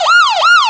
Gravis Ultrasound Patch
03_SIREN.mp3